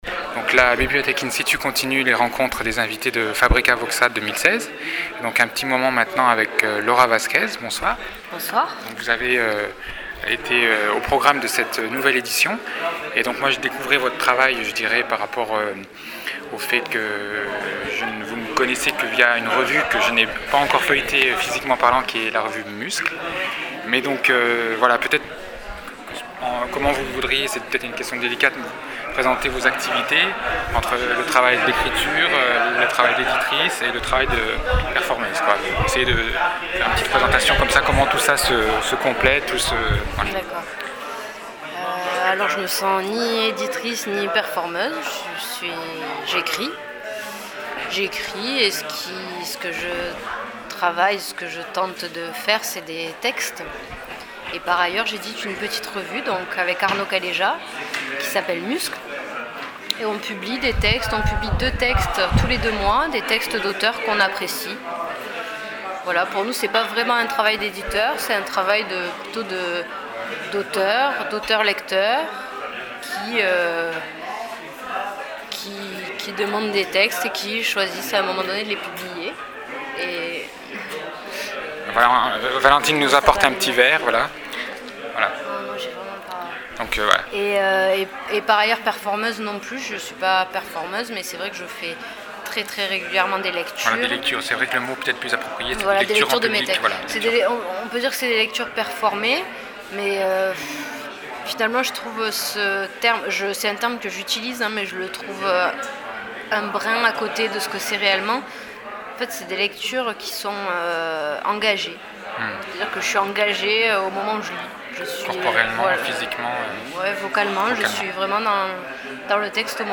Lecture synestésique avec la performance qu’elle est en train de concevoir dans sa tête, patiemment, silencieusement, entre for intérieur et observation du réel.